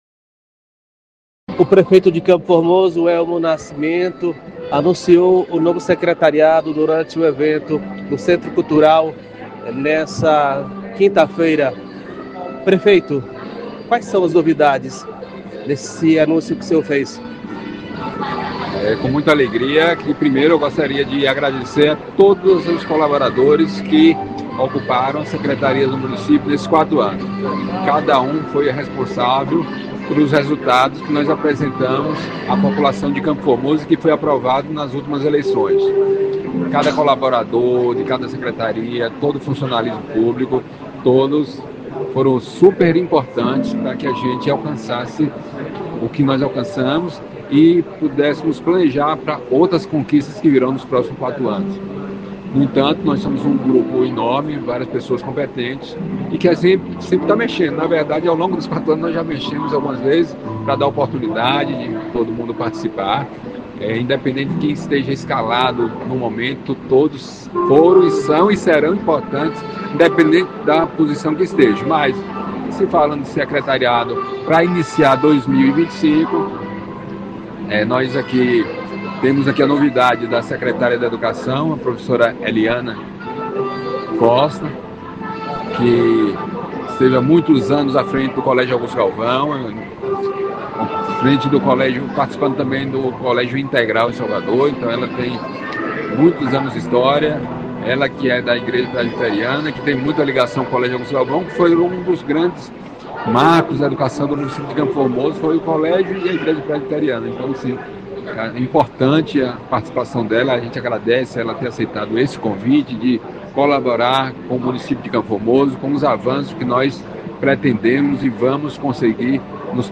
Prefeito de Campo Formoso Elmo Nascimento – Anuncia Secretariado